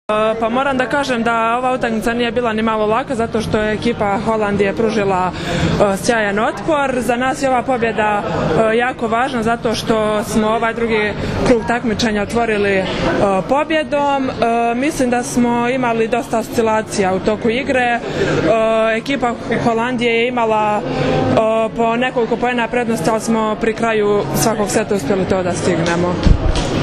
IZJAVA TIJANE BOŠKOVIĆ